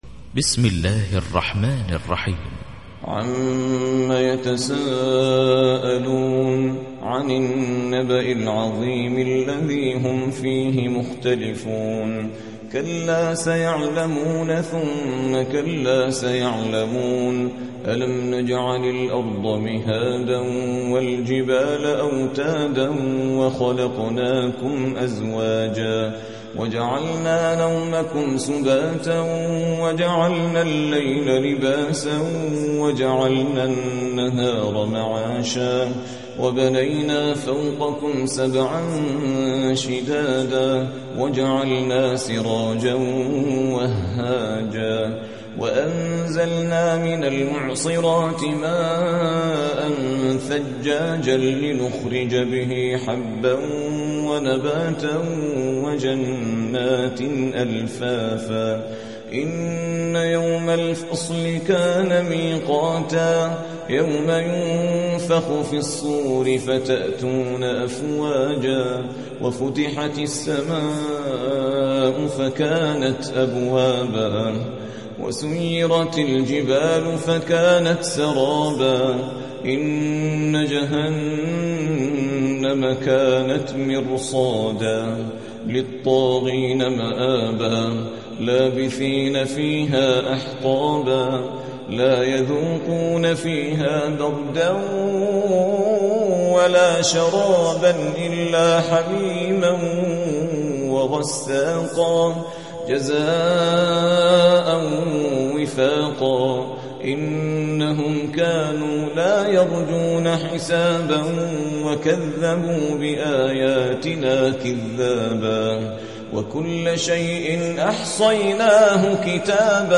78. سورة النبأ / القارئ